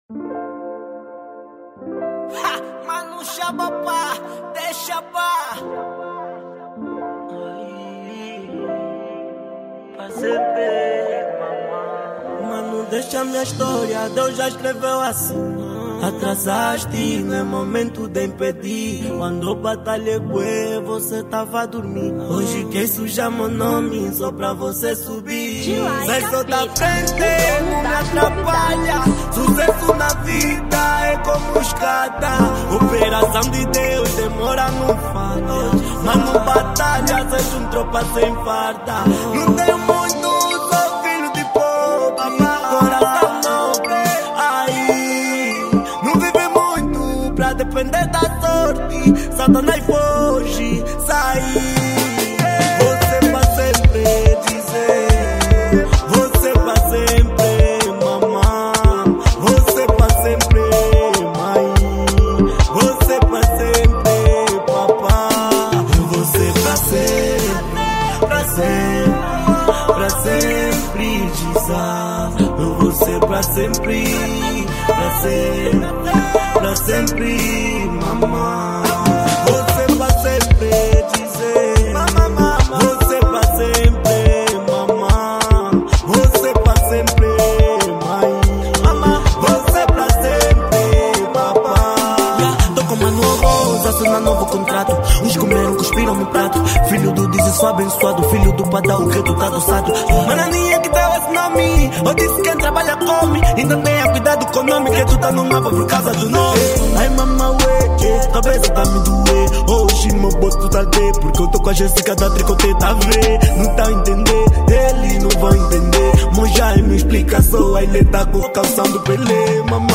Kuduro 2024